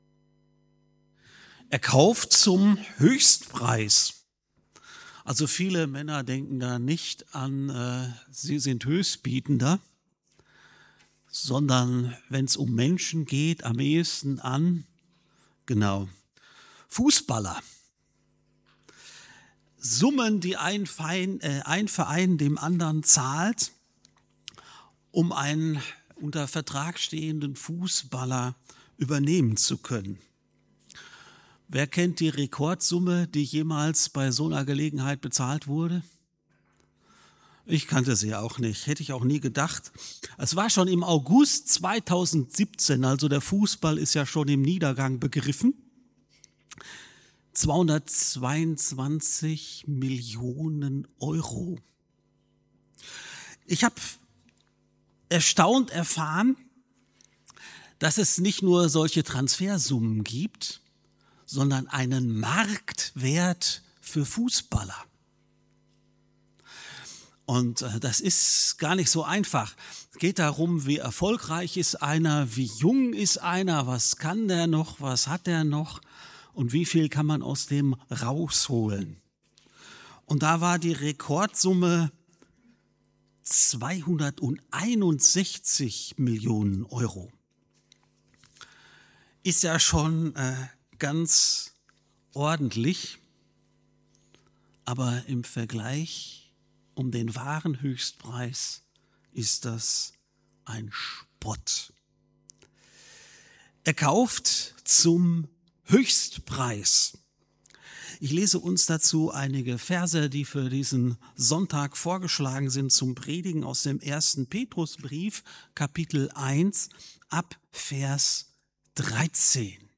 FeG Aschaffenburg - Predigt